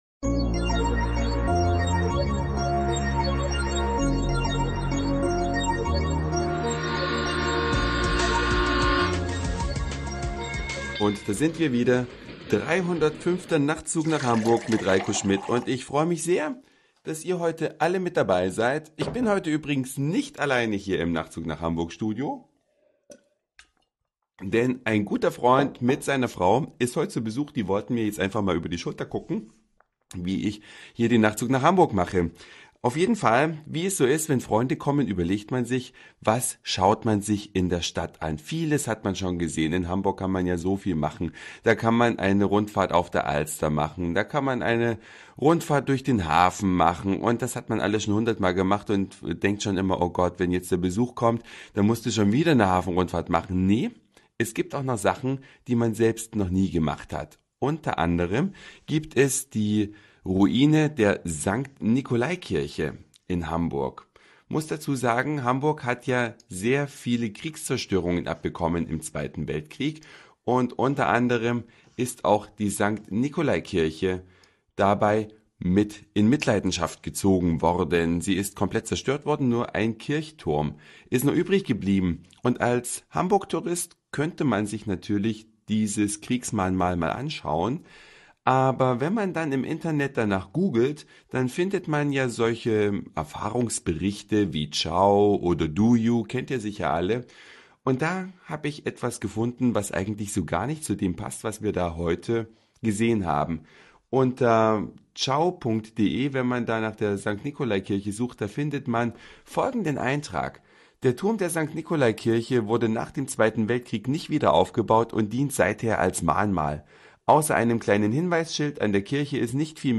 Eine Reise durch die Vielfalt aus Satire, Informationen, Soundseeing und Audioblog.
Wahrzeichen Hamburgs, der Michel, aufgenommen von der 75 m hohen
Plattform des St.-Nicolai-Mahnmals: